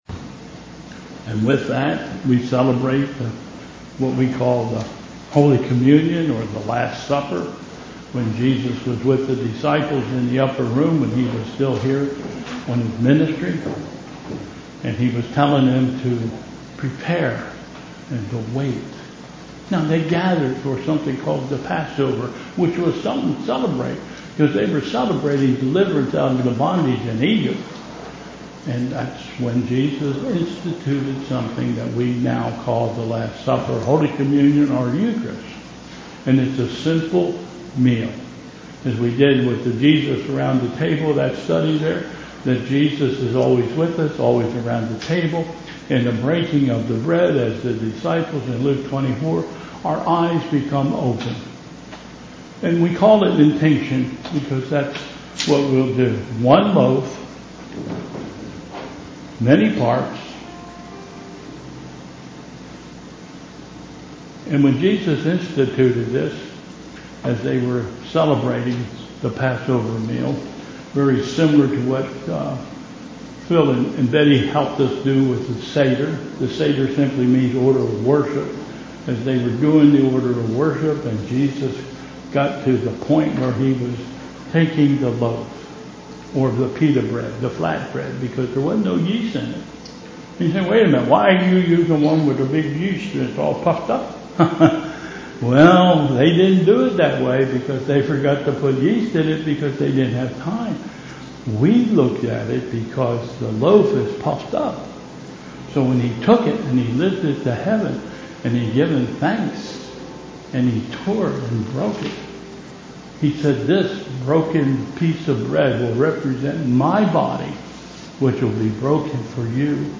Bethel Church Service